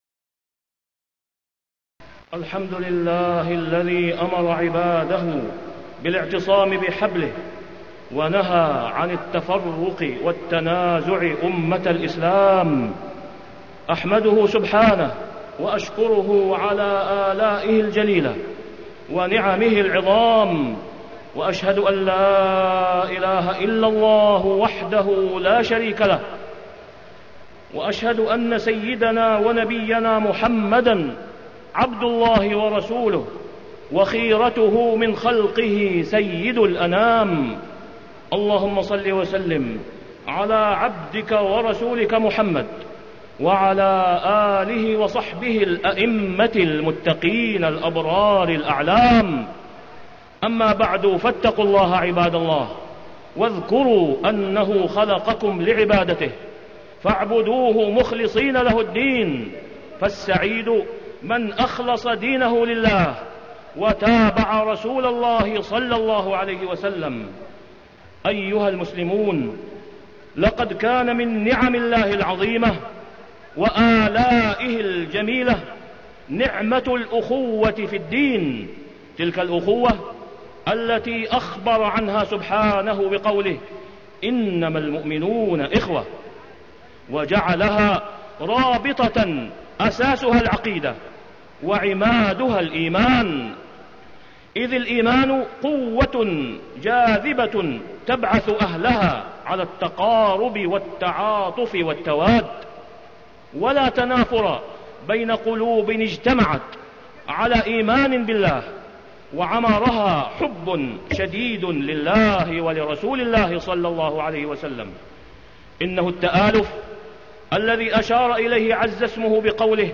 تاريخ النشر ١٤ محرم ١٤٢٨ هـ المكان: المسجد الحرام الشيخ: فضيلة الشيخ د. أسامة بن عبدالله خياط فضيلة الشيخ د. أسامة بن عبدالله خياط واعتصموا بحبل الله جميعا ولا تفرقوا